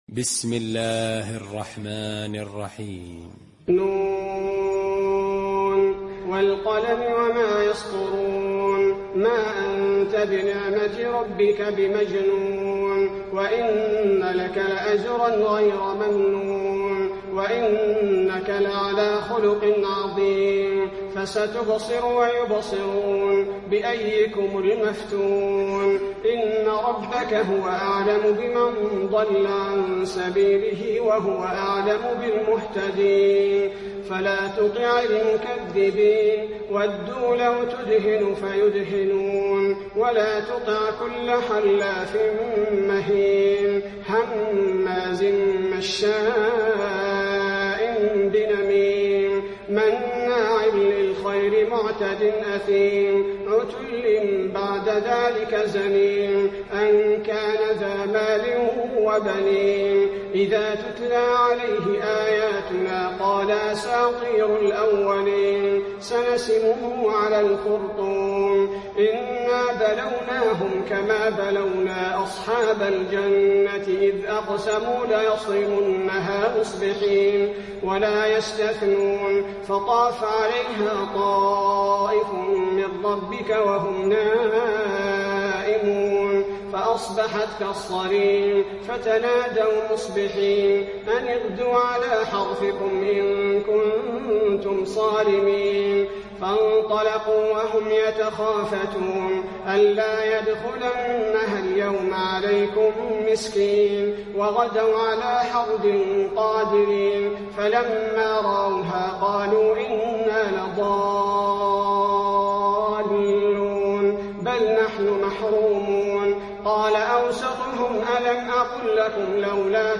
المكان: المسجد النبوي القلم